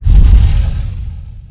Puerta.wav